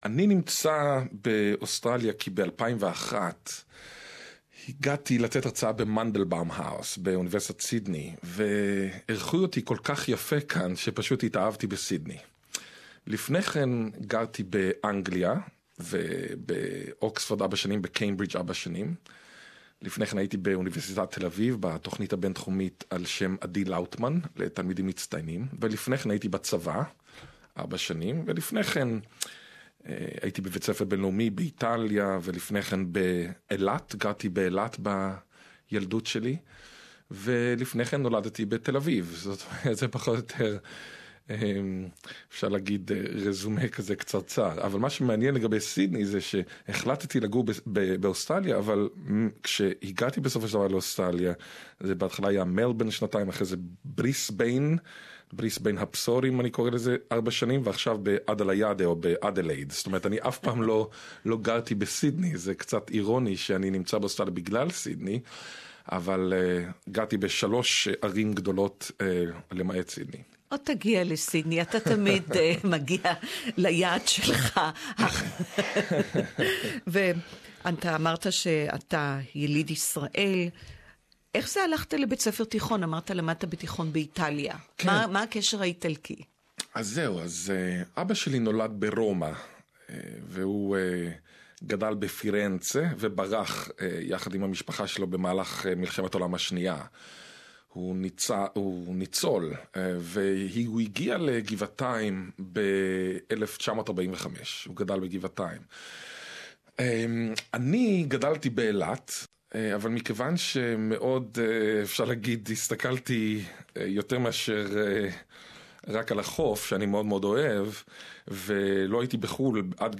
Prof Ghil'ad Zuckermann Interview in Hebrew